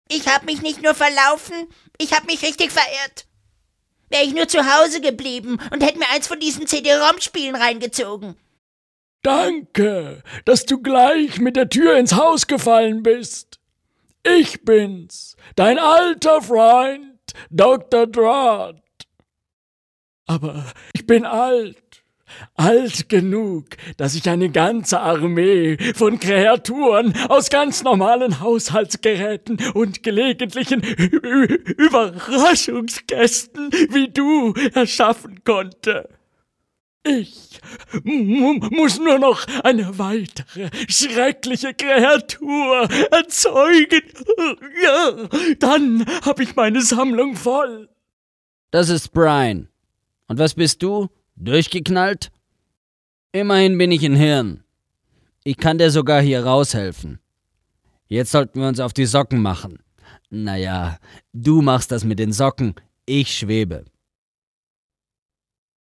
deutscher Sprecher und Schauspieler. Mitteltiefe bis tiefe Stimmlage.
Sprechprobe: Sonstiges (Muttersprache):
voice over talent german